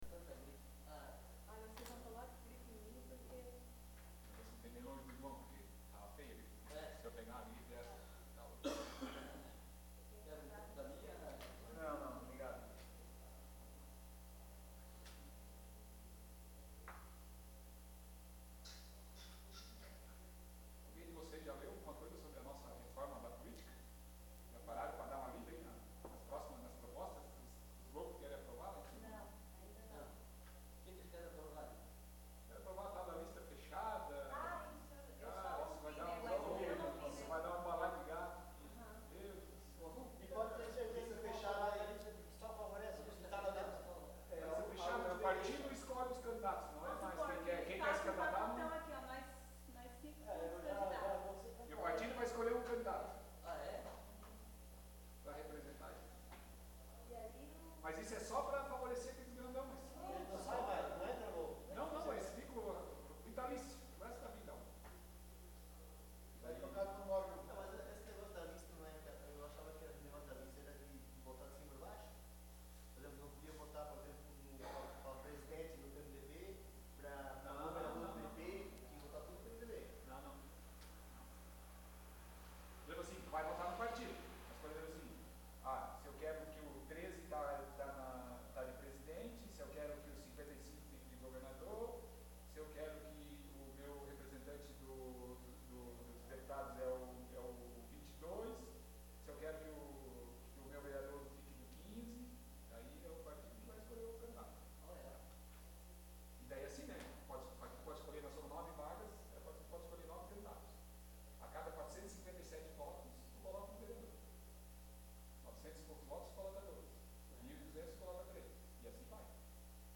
Áudio da Sessão Ordinária de 17 de abril de 2017.